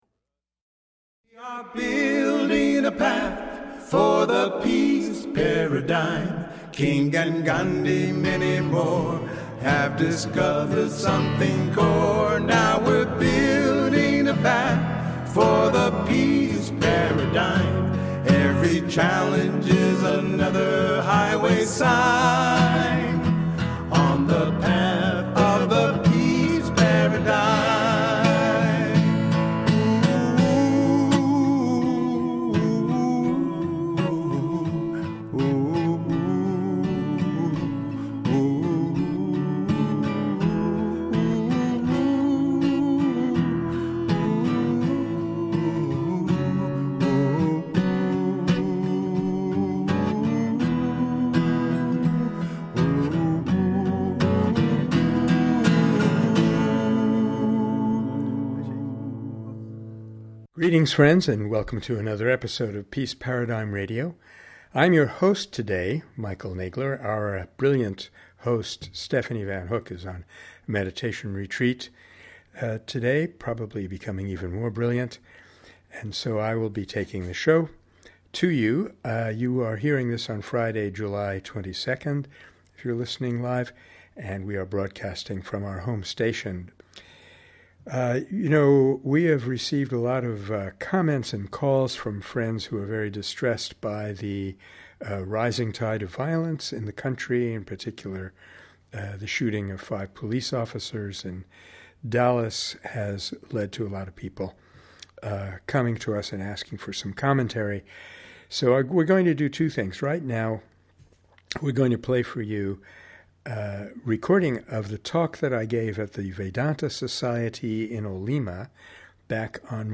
Vedanta teaches that the essence of all beings and all things is Spirit, infinite and eternal, unchanging and indivisible. Don t miss this great talk on Universal Spiritual Humanism, followed by a short but sweet episode of Nonviolence in the News.